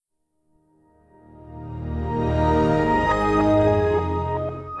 Edited the XP startup/shutdown sounds in Audacity and reversed them.
Windows XP Startup.wav